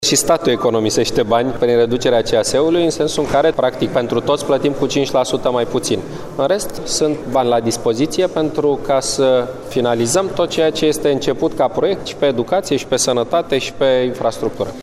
Rectificarea bugetară, care va fi discutată în şedinţa de mâine a Guvernului, nu va aduce o scădere a banilor de la sănătate şi educaţie, a declarat, astăzi, premierul Victor Ponta.